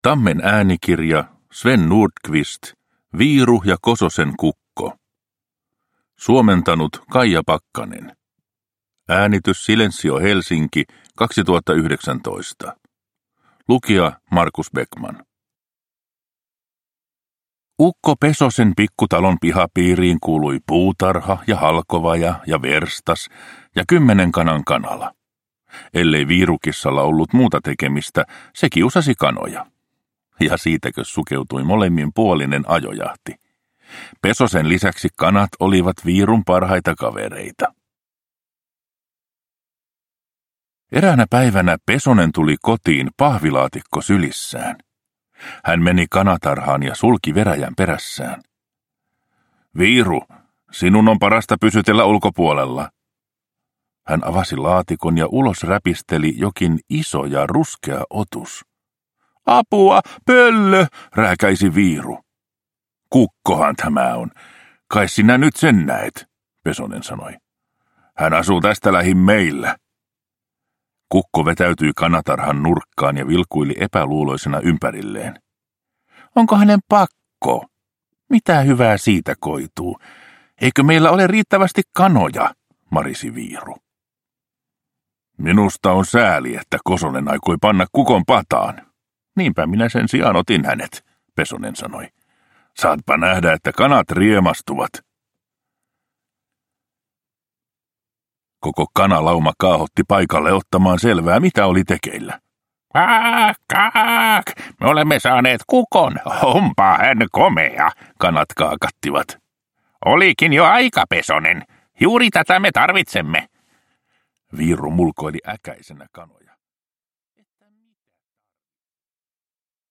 Viiru ja Kososen kukko – Ljudbok